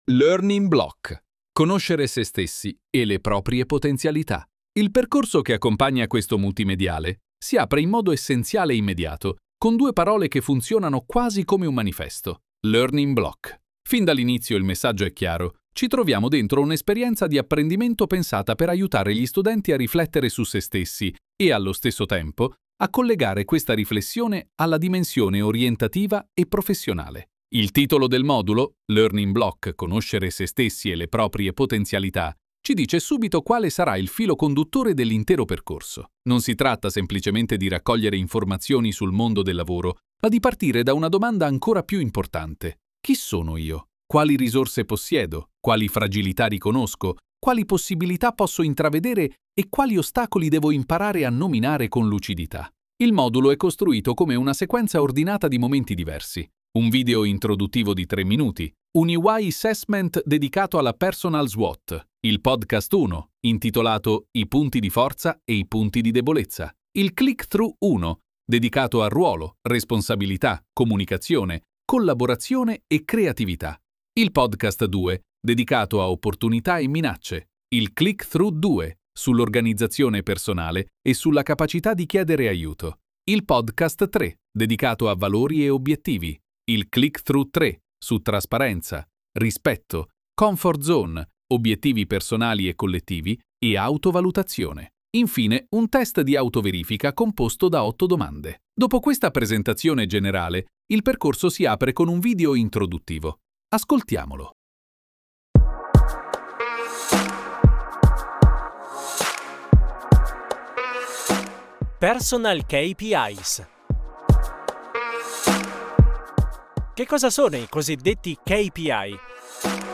Lezione n°1 Conoscere se stessi e le proprie potenzialità